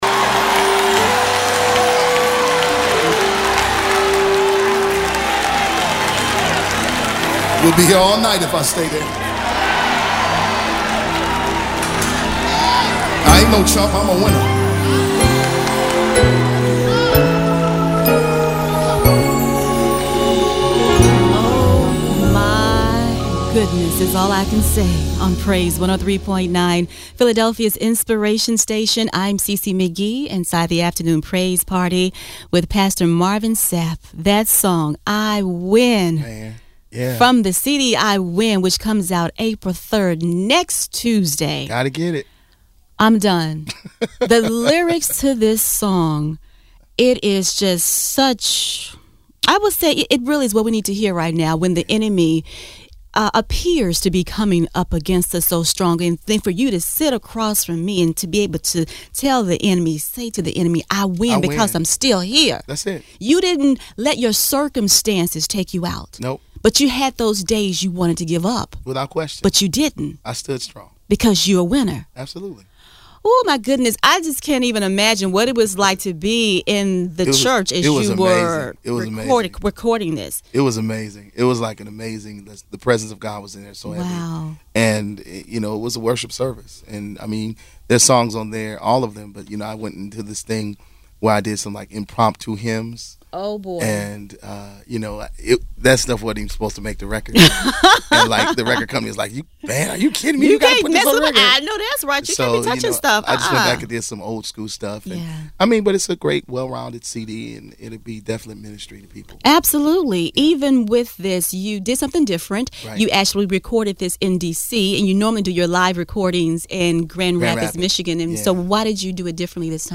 marvin-sapp-interview-part-2.mp3